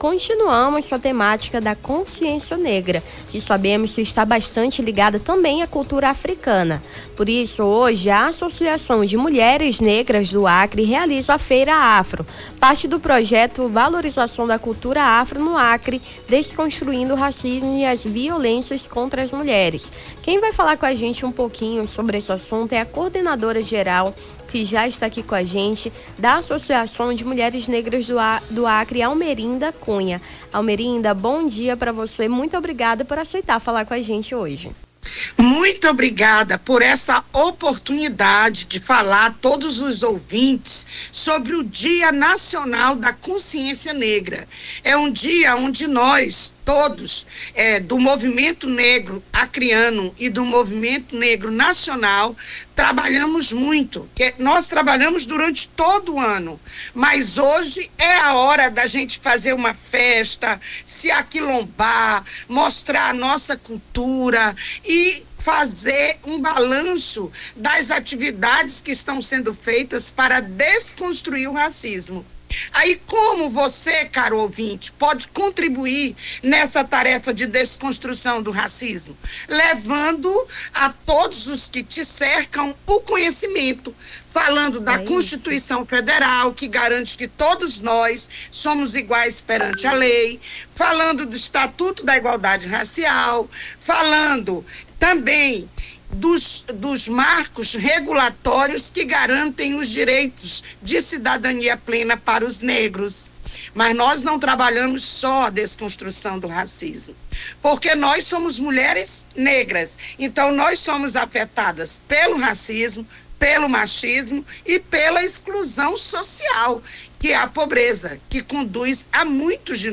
Nome do Artista - CENSURA - ENTREVISTA FEIRA CONSCIÊNCIA NEGRA (20-11-24).mp3